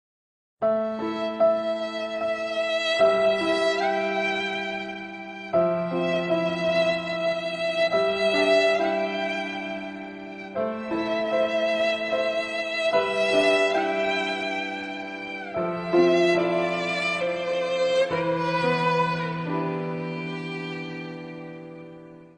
Play Sad Violin - SoundBoardGuy
PLAY Sad Violin (the meme one)
sad-violin.mp3